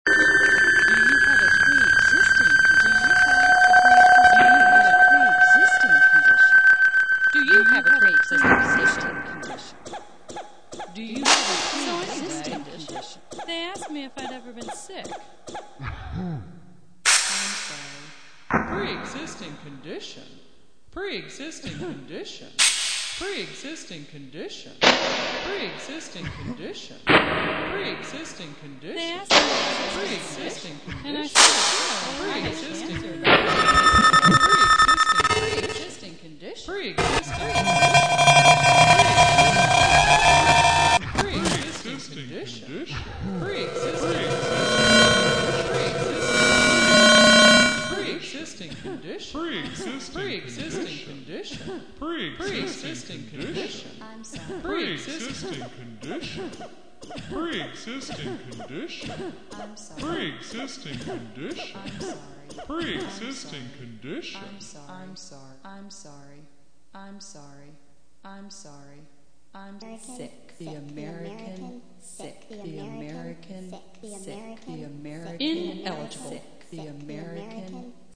voice used in classic voiceover/narrative style.